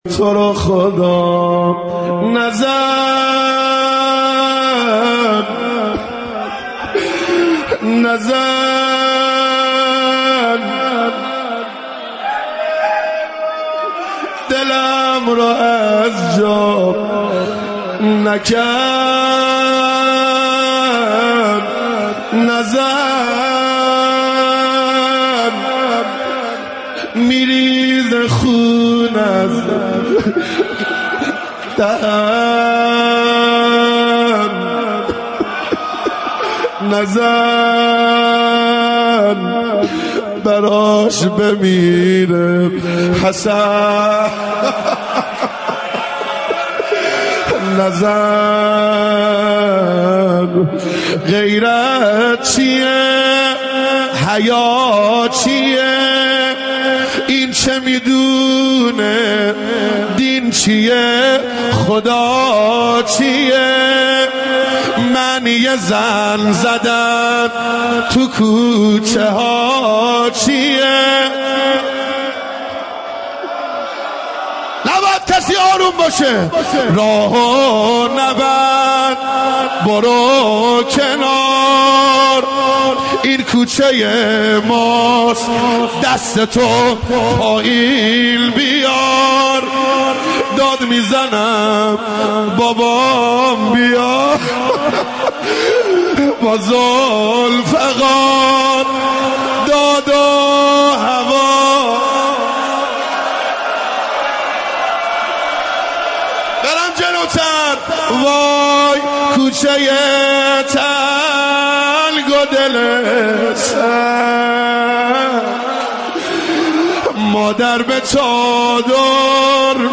روضه مظلومیت و شهادت بی بی دوعالم حضرت زهرا(س) با مداحی